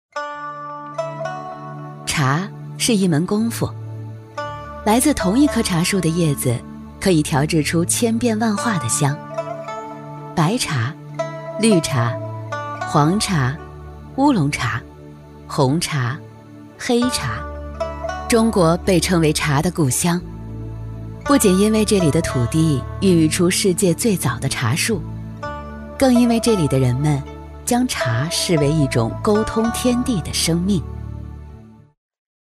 女42-纪录片-茶
女42-纪录片-茶.mp3